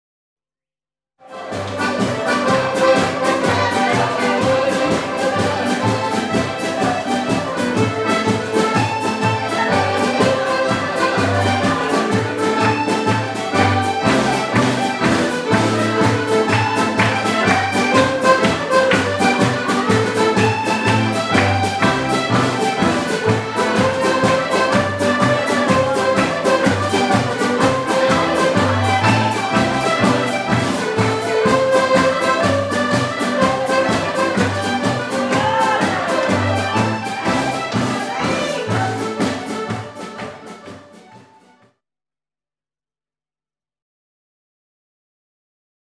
Jigs